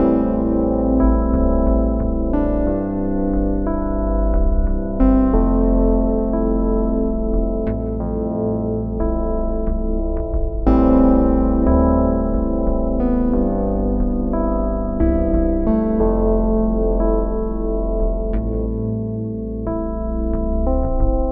标签： 90 bpm Hip Hop Loops Pad Loops 3.59 MB wav Key : A
声道立体声